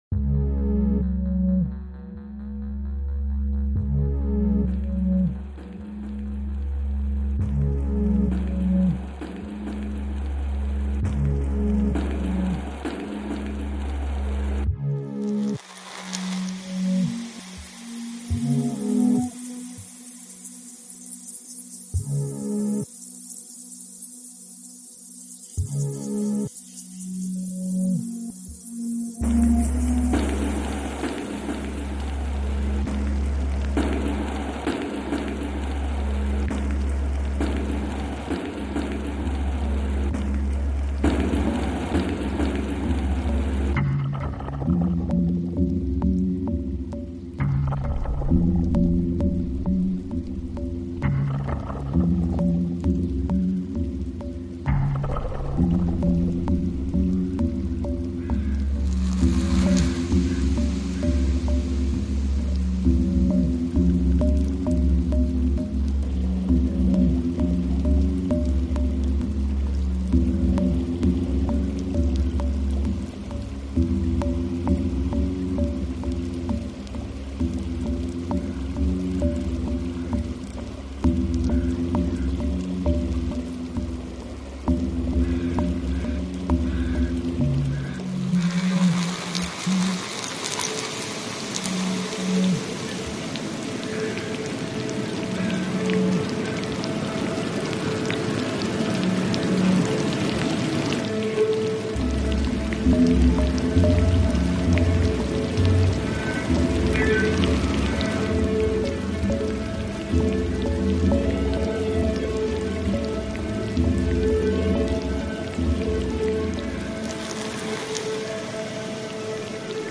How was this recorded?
Little Tribune Bay barnacle recording reimagined